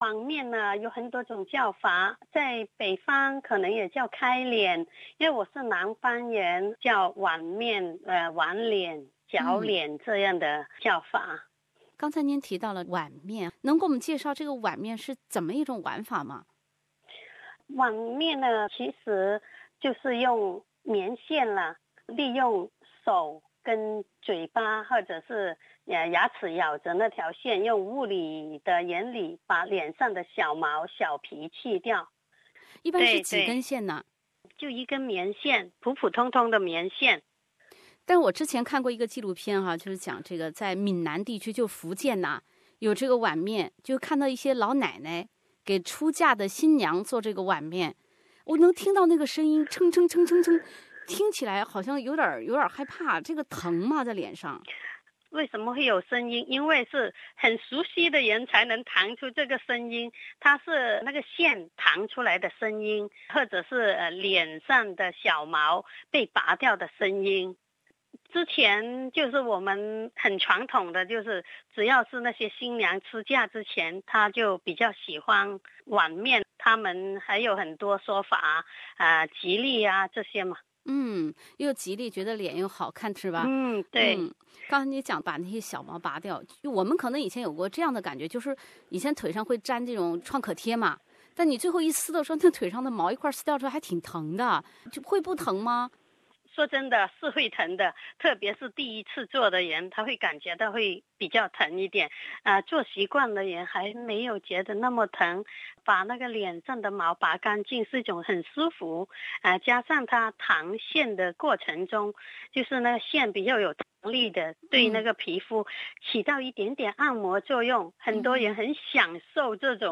Threading is a traditional technique to remove hair that mostly on eyebrows, lips, cheeks, and chin. The name derives from the cotton threads that are twisted to pull the hair from the root. Interview